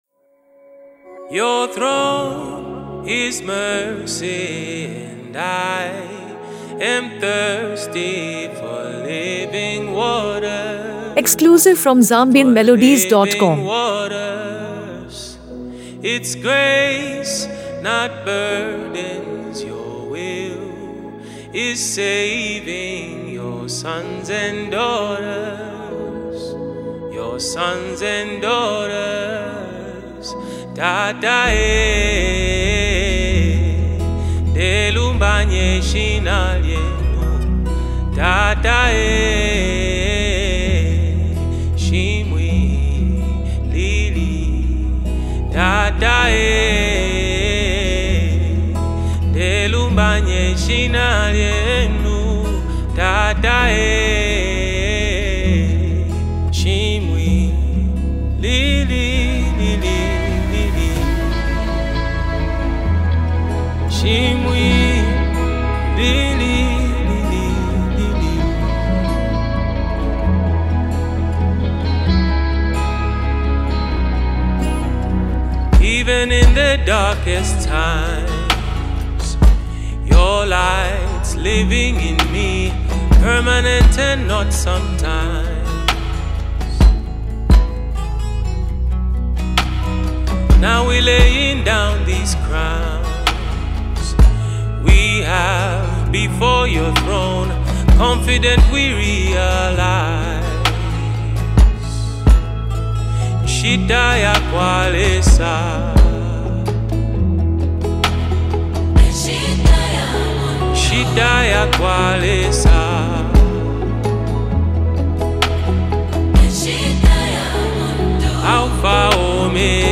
” a soul-stirring blend of gospel and Afro-soul
Genre: Gospel